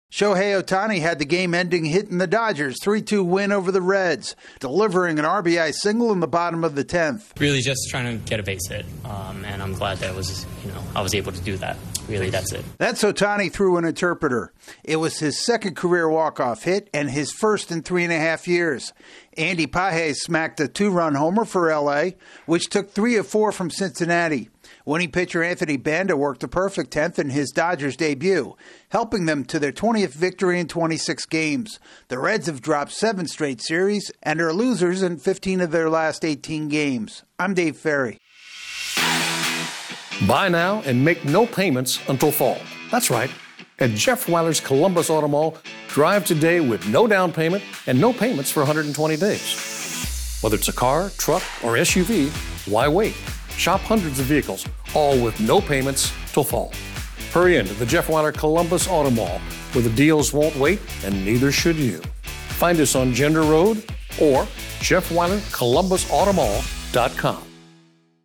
The Dodgers win in extra innings. AP correspondent